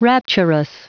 Prononciation du mot rapturous en anglais (fichier audio)
Prononciation du mot : rapturous